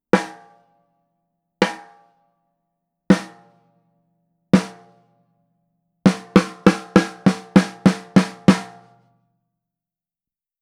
実際の録り音
スネア
58ドラムスネア.wav